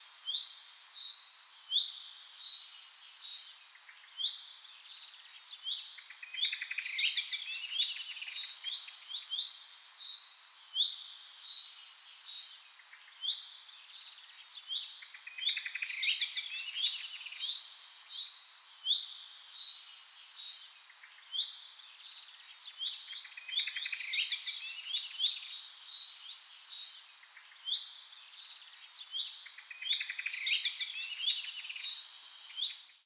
صدای پرندگان در صبح جنگل: